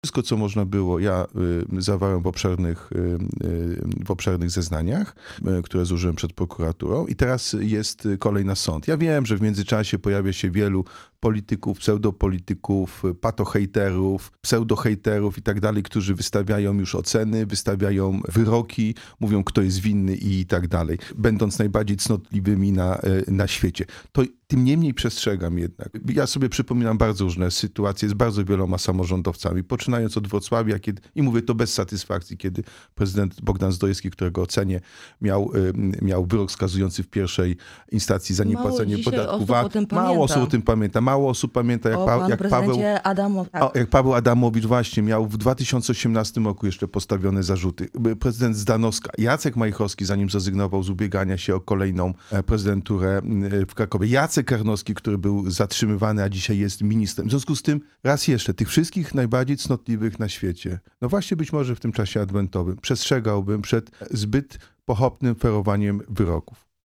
Projekt budżetu na 2025 r., audyt w piłkarskim Śląsku Wrocław, sprawa zarzutów ws. Collegium Humanum – to główne tematy w naszej rozmowie z prezydentem Wrocławia.